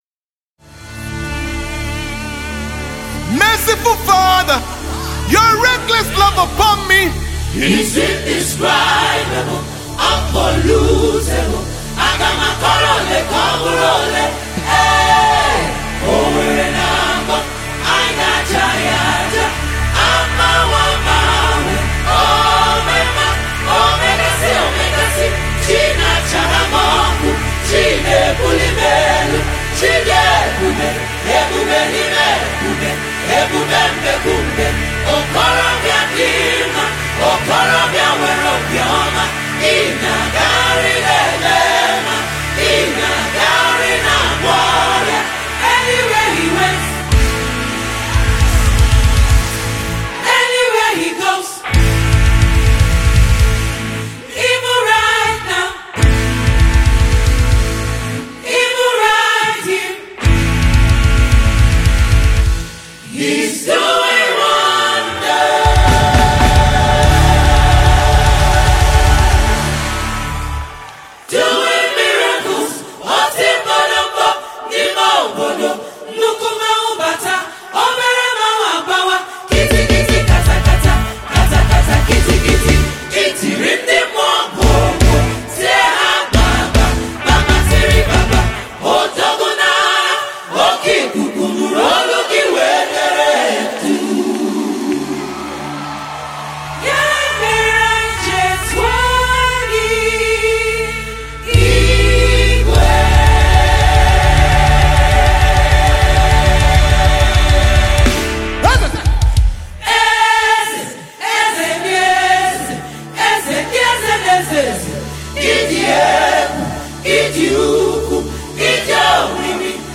Talented Nigerian Gospel singer-songwriter and performer